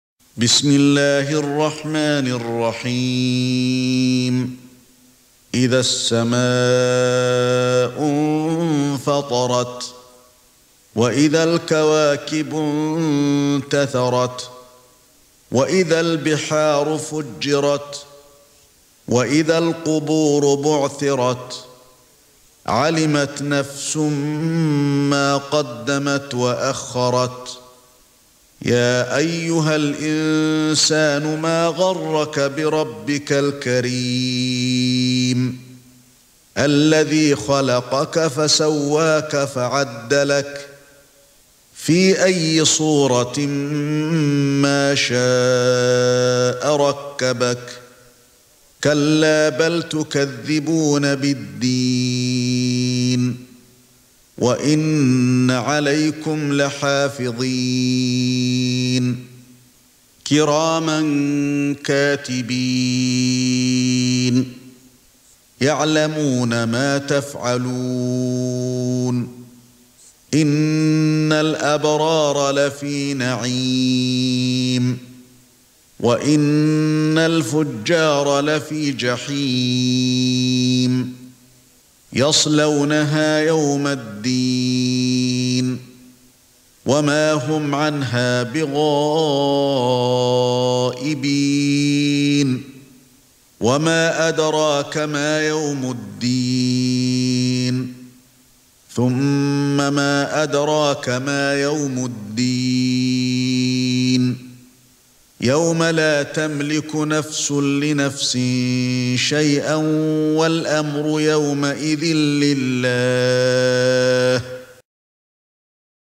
سورة الانفطار ( برواية قالون ) > مصحف الشيخ علي الحذيفي ( رواية قالون ) > المصحف - تلاوات الحرمين